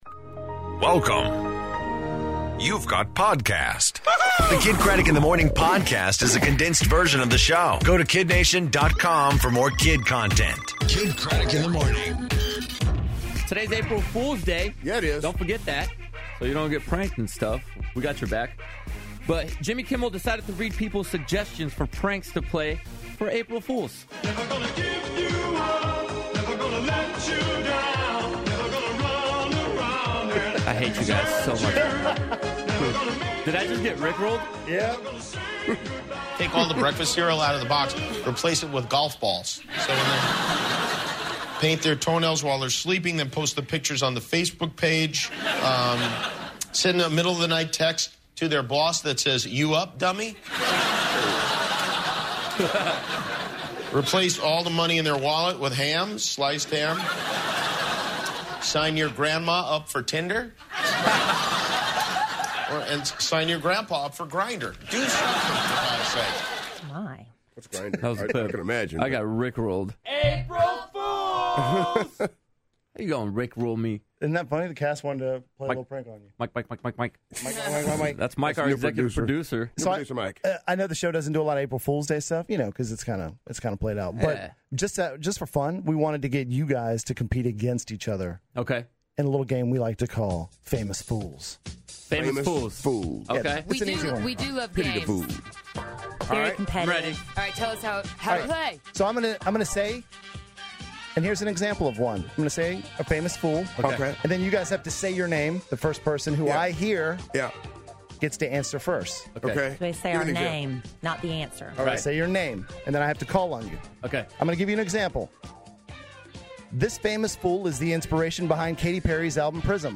April Fool's Day, Beauty Tips, And Nick Cannon On The Phone